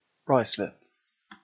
Summary Description En-Ruislip.ogg English: Pronunciation of Ruislip in London as counterintuitive vowel, and with remaining intonation inherently neutral (applies across majority of dialects).
En-Ruislip.ogg